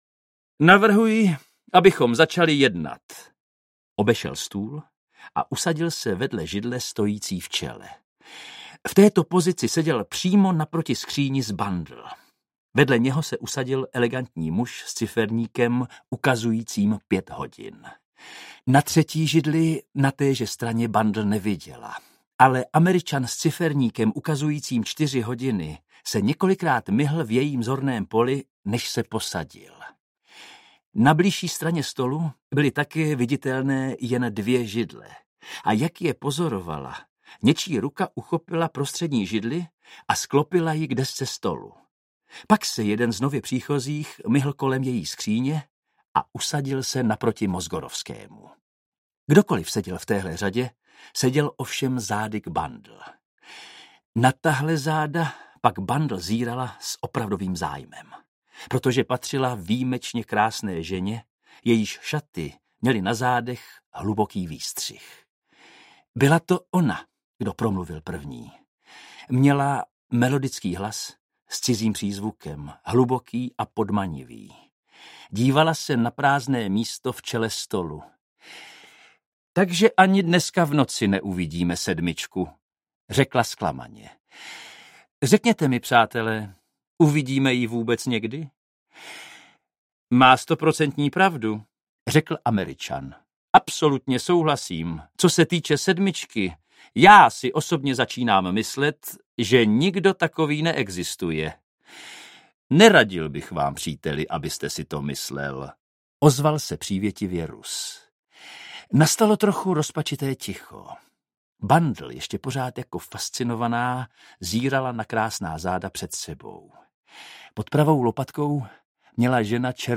Záhada sedmi ciferníků audiokniha
Ukázka z knihy
Vyrobilo studio Soundguru.